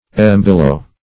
Embillow \Em*bil"low\